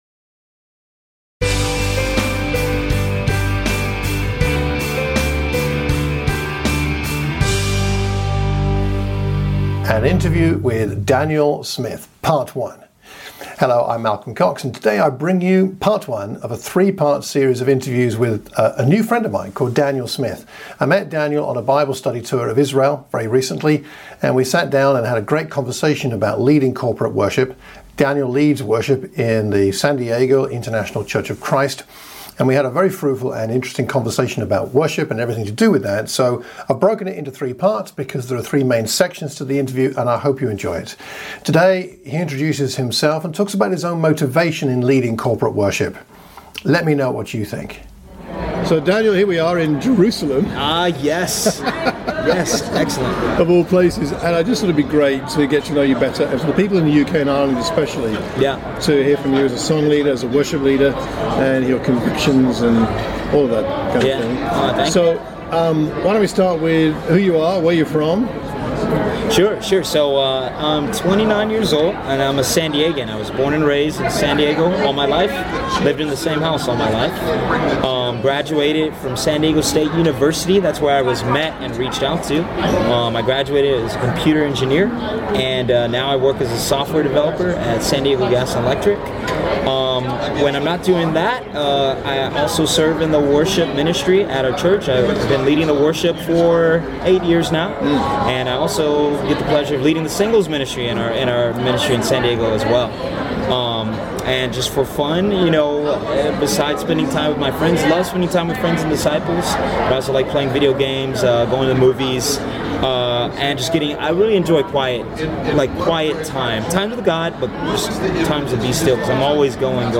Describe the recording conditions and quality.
We sat and chatted about all things worship.